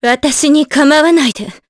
Maria-vox-select_jp.wav